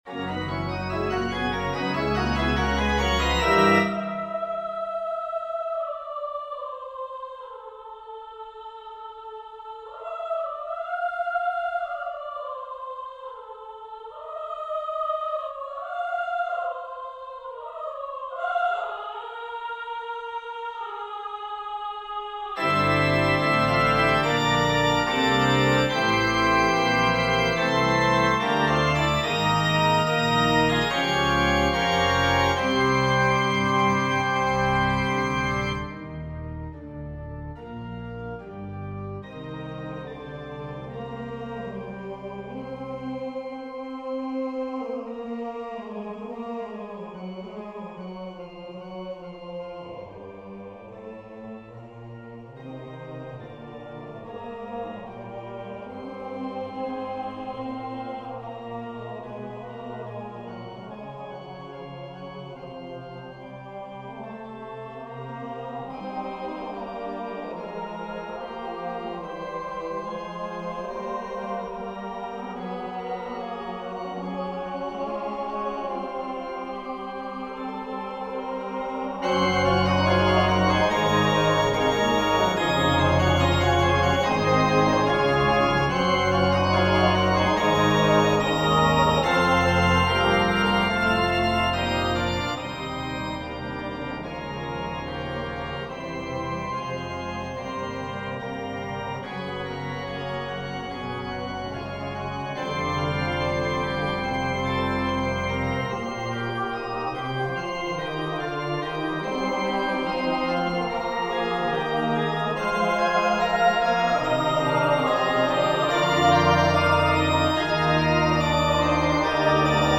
4vv Voicing: SATB Genre: Secular, Cantata
Language: English Instruments: Keyboard